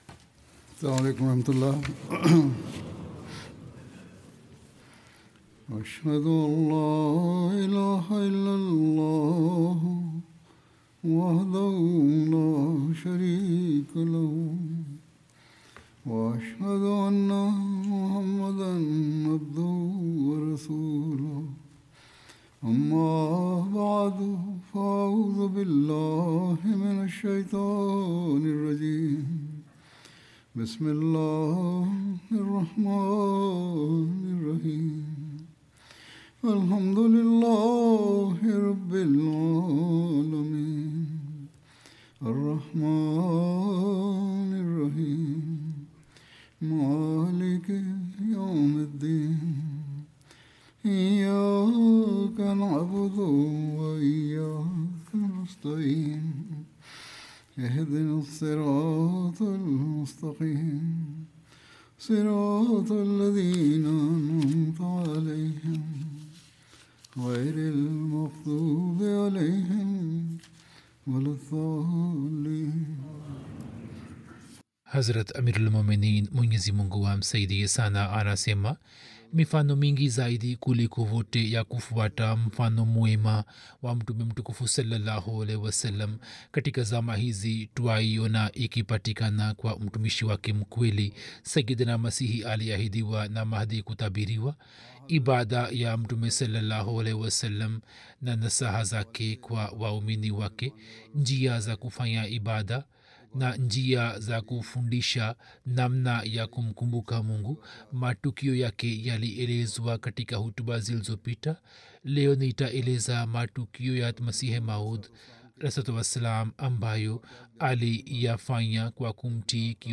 Swahili Friday Sermon by Head of Ahmadiyya Muslim Community